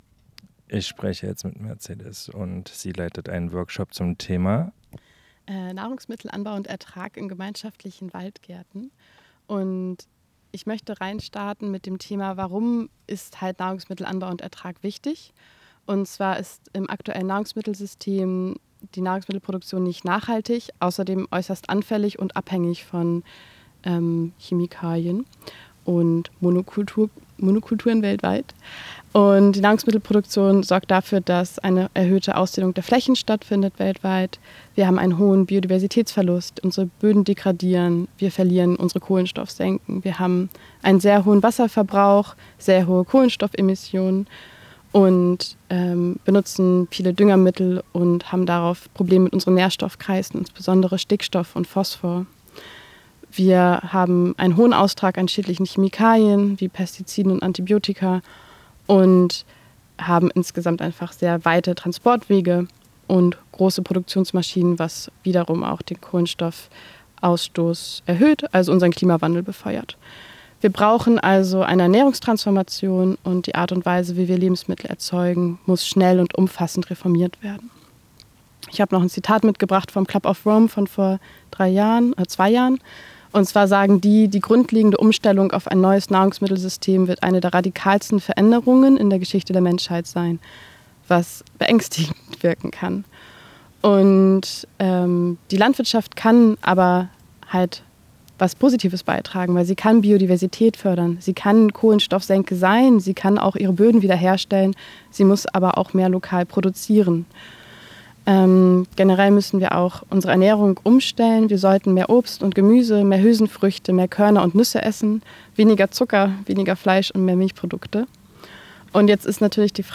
Urban Gardening Sommercamp in der Alten Gärtnerei, Sondersendung vom 04.09.2024